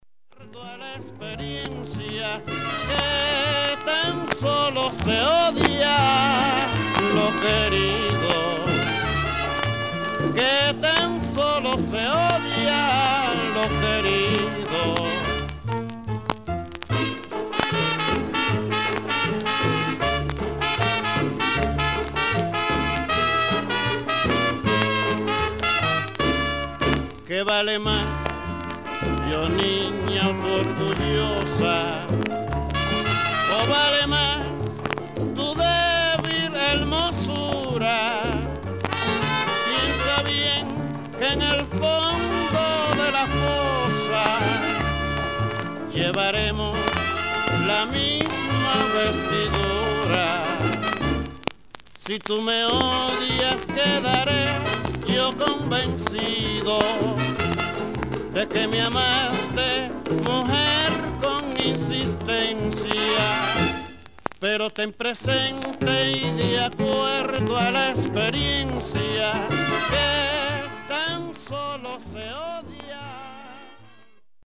Vintage Cuban Mambo 45'S !!!